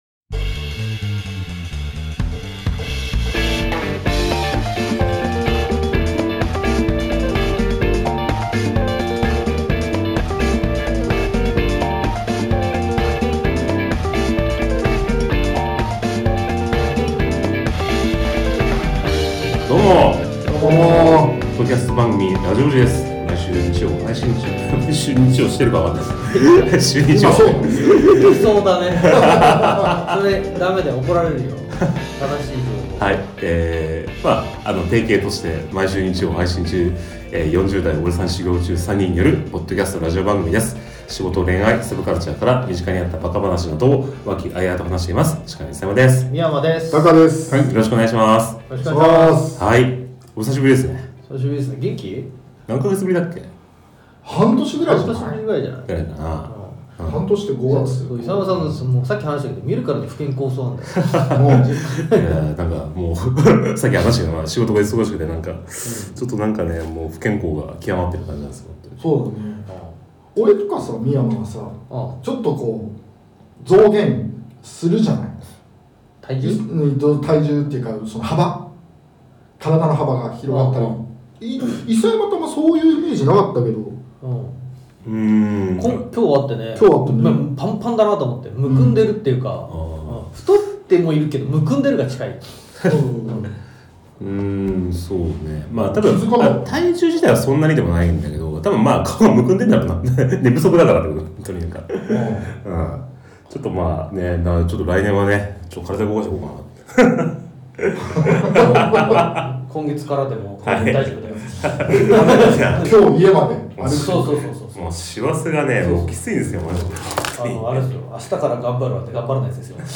30代オジサン初心者3人によるポッドキャストラジオ。仕事、恋愛、サブカルから身近にあった馬鹿話等を和気あいあいと話しています。